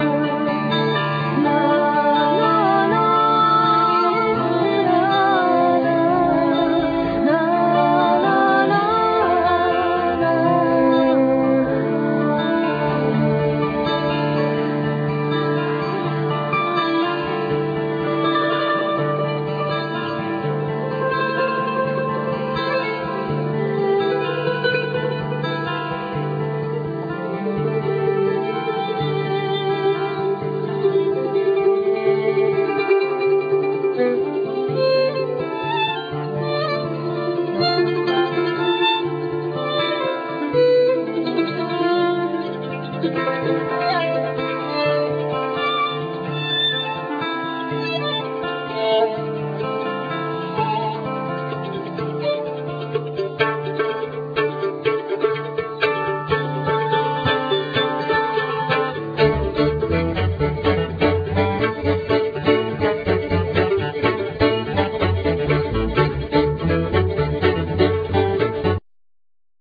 Vocal
Violin
Mandolin,Koncovka
Double-bass
Gitar
Clarinet,Tarogato
Banjo,Low-whistle,Tarogato